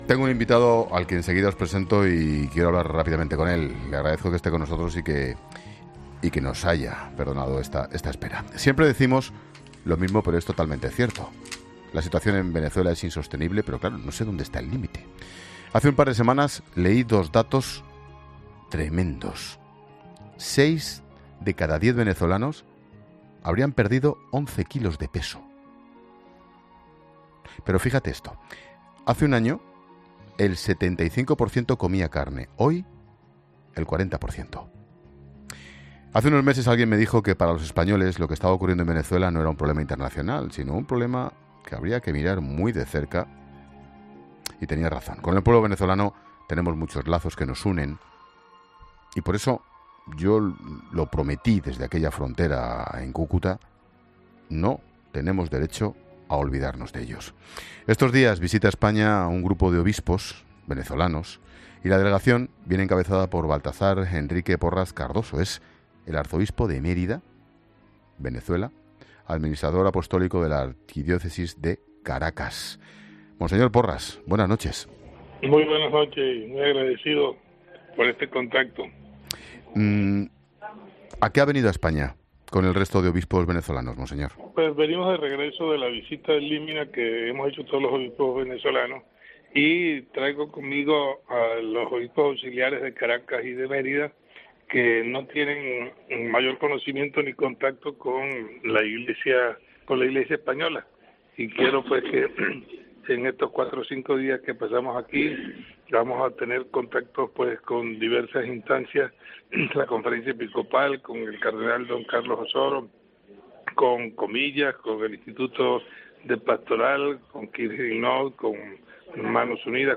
La delegación la encabeza por el cardenal Baltazar Enrique Porras Cardozo, arzobispo de Mérida y Administrador Apostólico de la Archidiócesis de Caracas, que ha pasado por los micrófonos de 'La Linterna', donde ha advertido de que hay que preocuparse por las personas que se han quedado en Venezuela, los que no han podido salir del país.